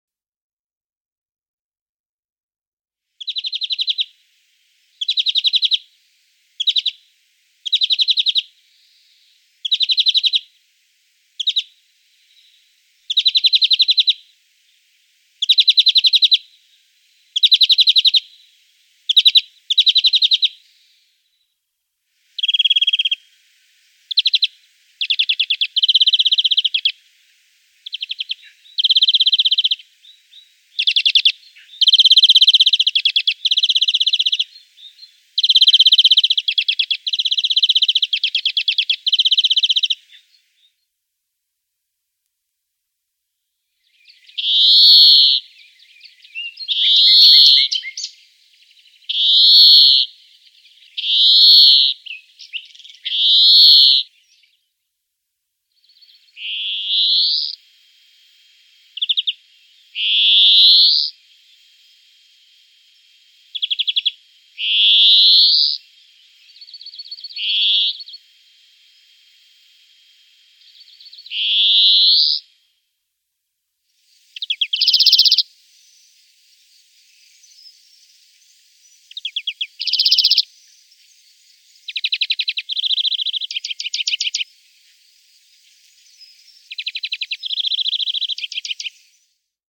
Grünfink
Grünfink(.mp3) mit Bläuling